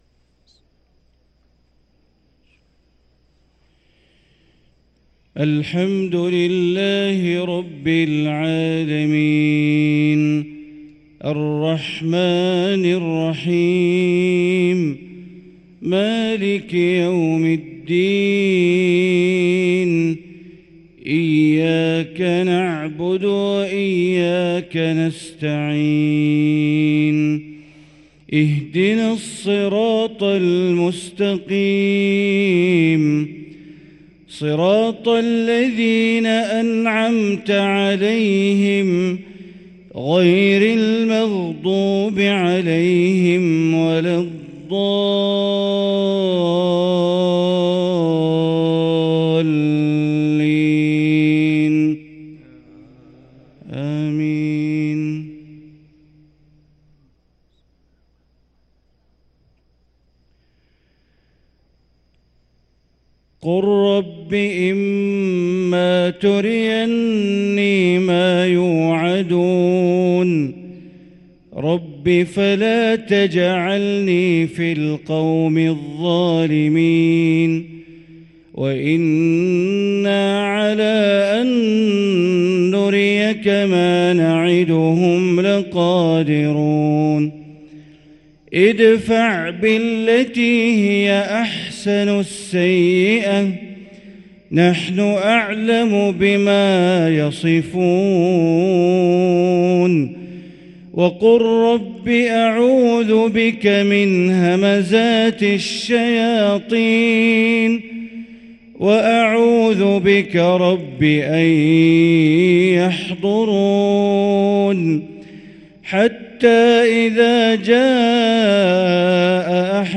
صلاة الفجر للقارئ بندر بليلة 16 شعبان 1444 هـ